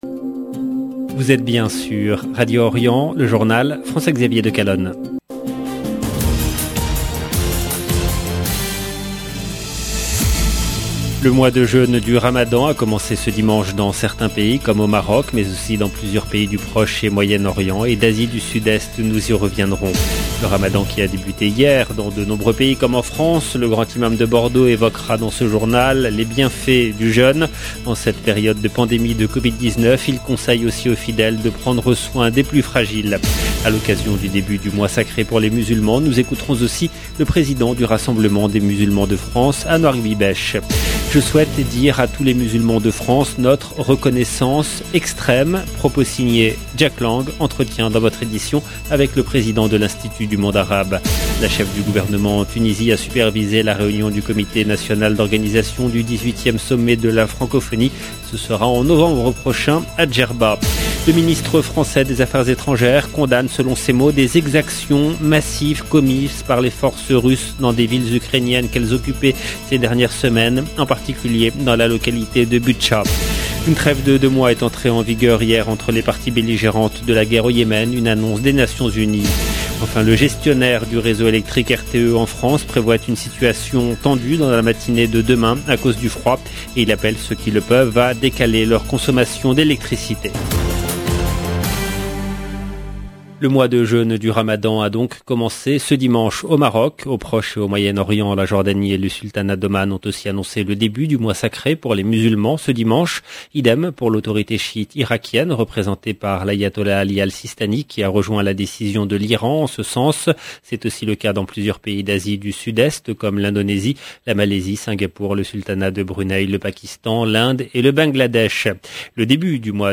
Entretien dans votre édition avec le président de l’Institut du Monde Arabe Jack Lang.